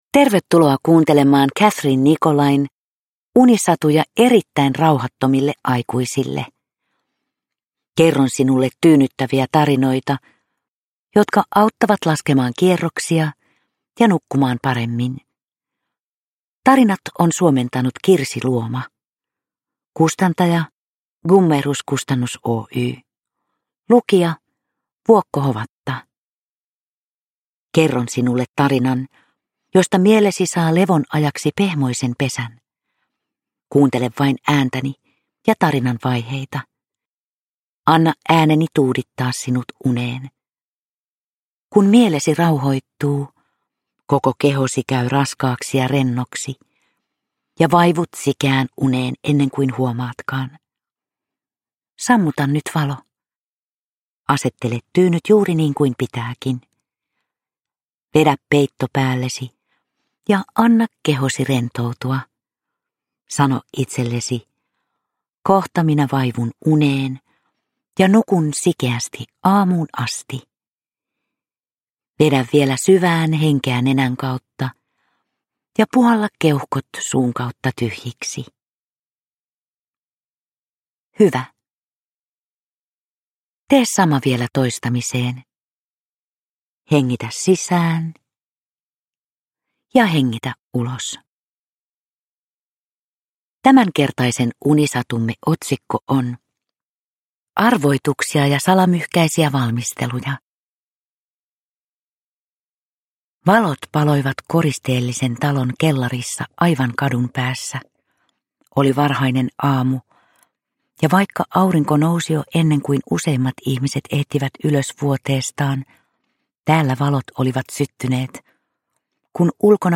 Vuokko Hovatan tyyni ääni saattelee kuulijan lempeästi unten maille.
Uppläsare: Vuokko Hovatta